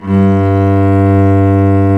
Index of /90_sSampleCDs/Roland - String Master Series/STR_Cb Bowed/STR_Cb3 Arco nv
STR CELLO 03.wav